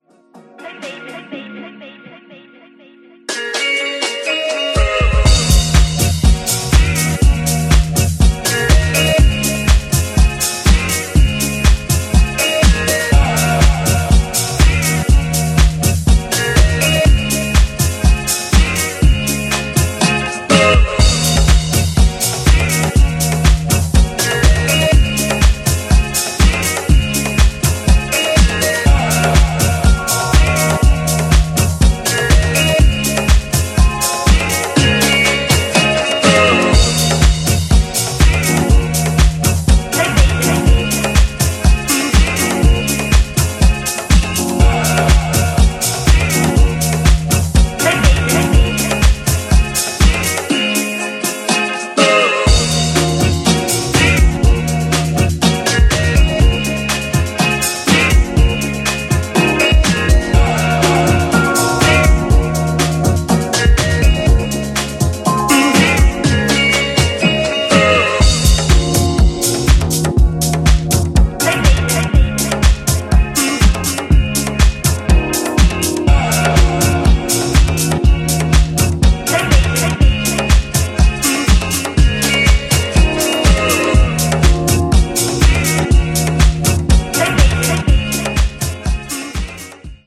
ジャンル(スタイル) NU DISCO / DISCO HOUSE / DEEP HOUSE